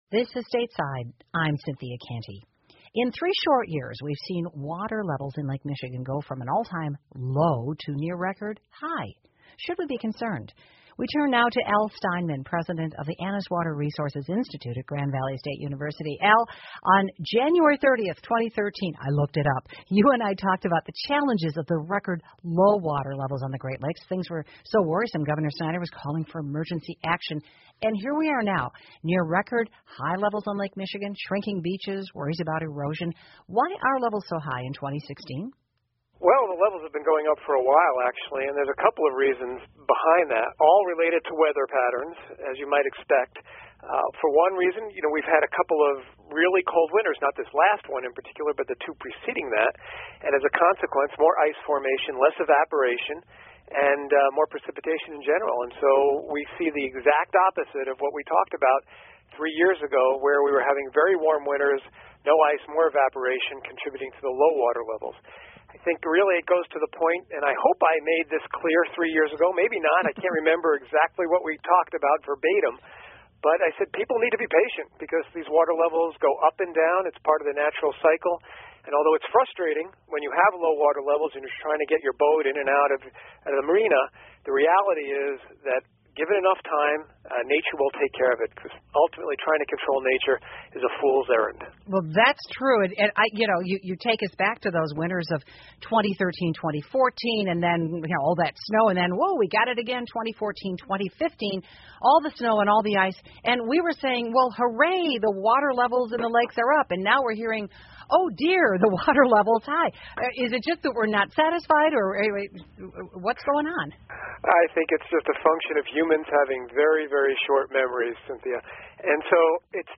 密歇根新闻广播 北美五大湖水位上升 但无需忧心 听力文件下载—在线英语听力室